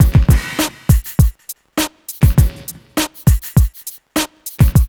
HF101BEAT1-R.wav